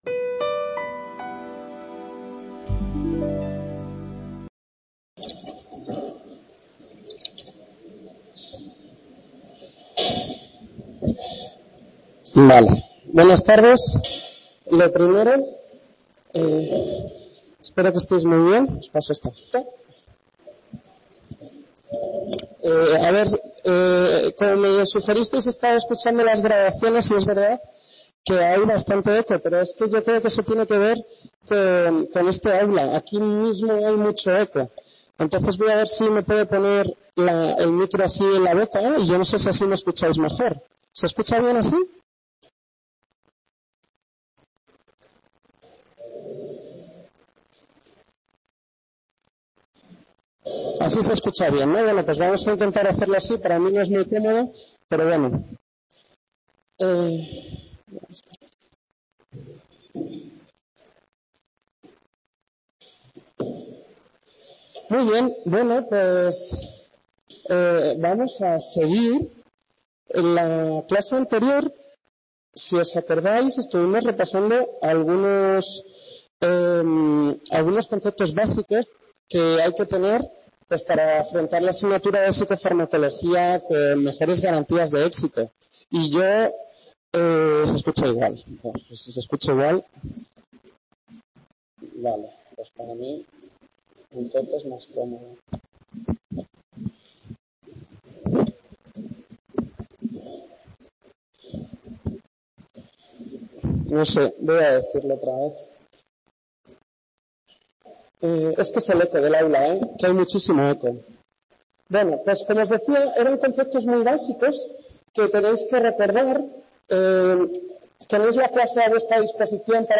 Primera Clase T1: Dopamina